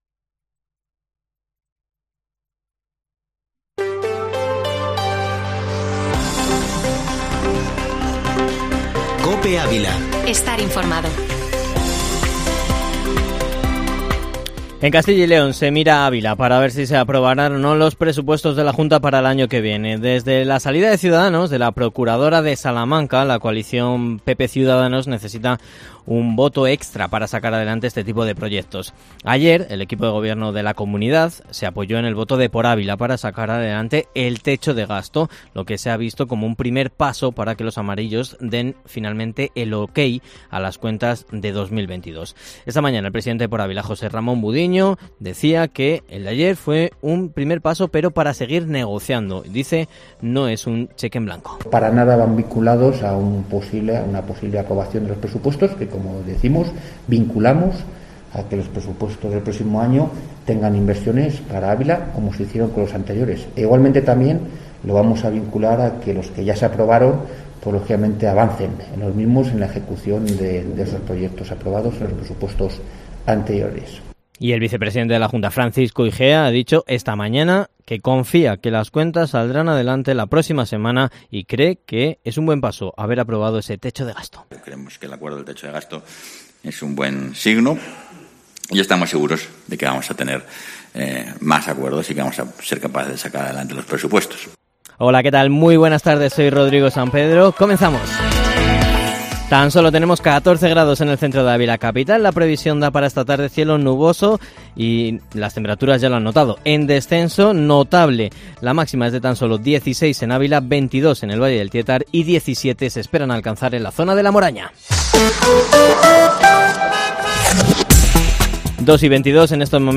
INFORMATIVO MEDIODÍA COPE ÁVILA 21 OCTUBRE 2021